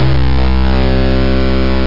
Fuzz Bass Sound Effect
Download a high-quality fuzz bass sound effect.
fuzz-bass.mp3